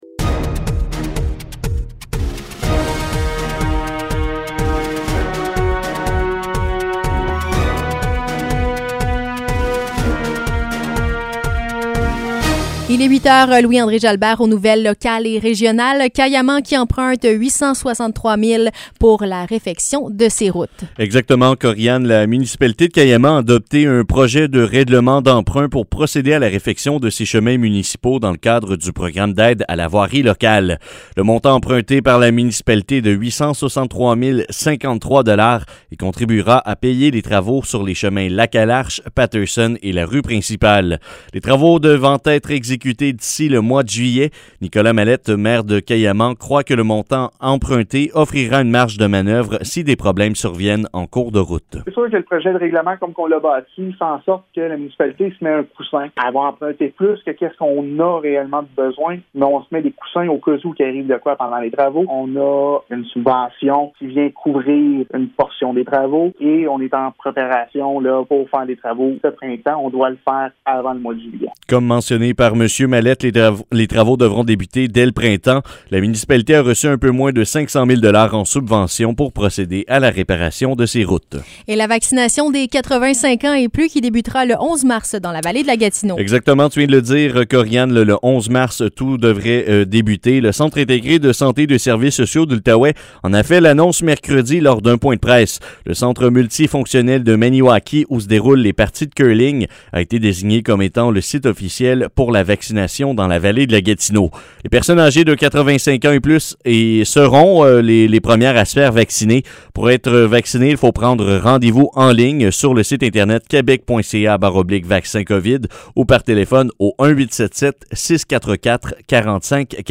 Nouvelles locales - 25 février 2021 - 8 h